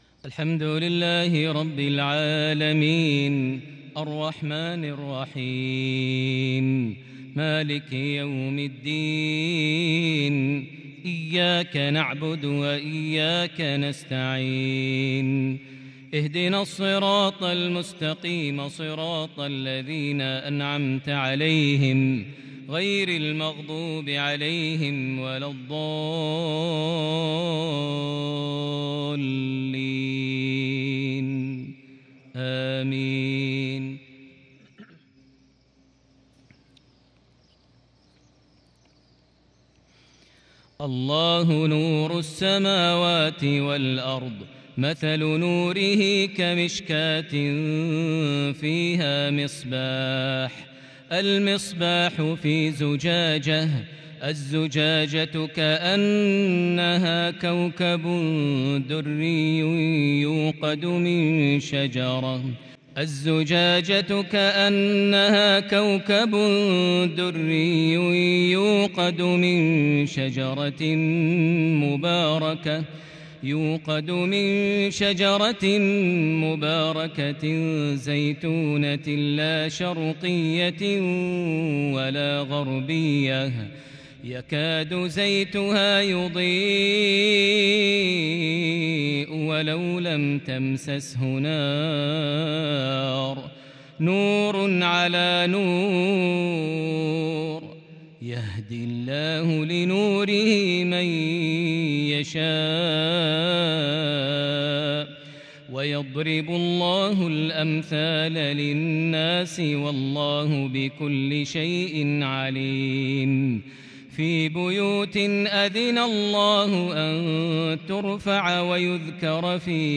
مغربية لاتوصف بتلاوة بديعة من سورة النور (35-40) | الأحد 18شوال 1442هـ > 1442 هـ > الفروض - تلاوات ماهر المعيقلي